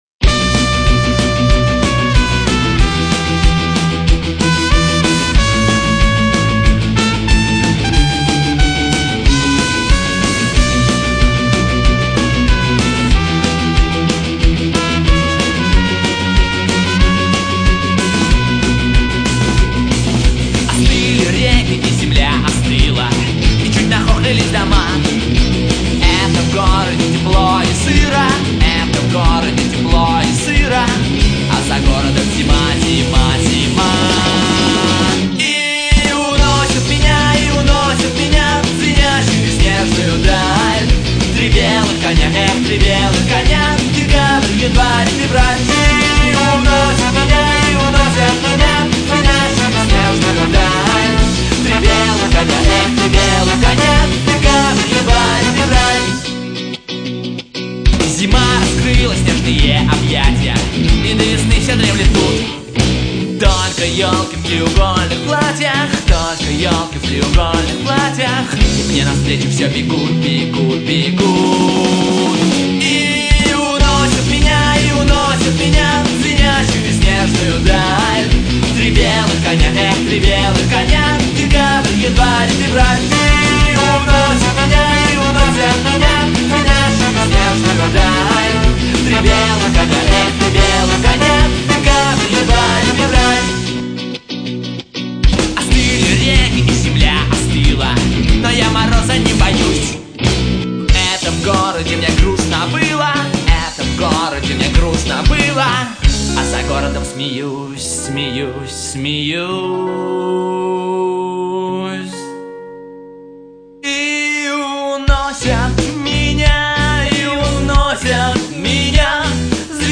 (Детский сад)